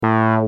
Free MP3 vintage Korg PS3100 loops & sound effects 7